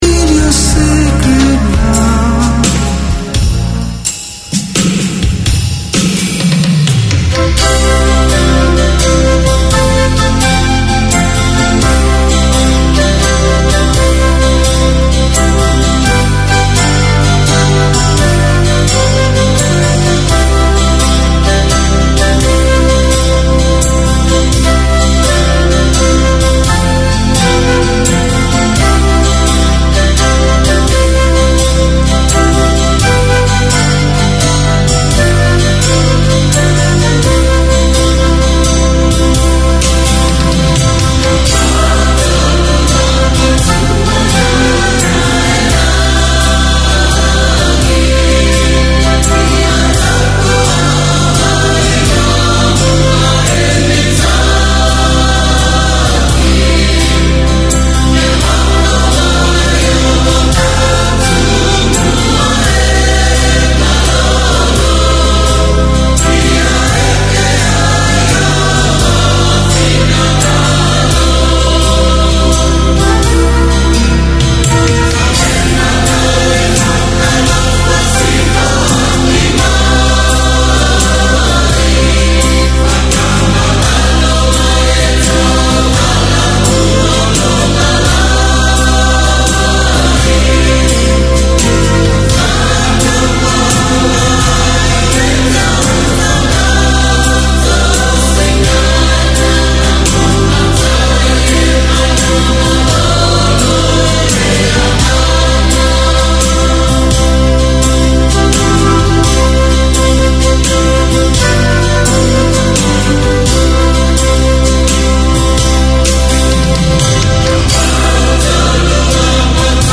Community Access Radio in your language - available for download five minutes after broadcast.
A comprehensive arts show featuring news, reviews and interviews covering all ARTS platforms: film, theatre, dance, the visual arts, books, poetry, music ... anything that is creative.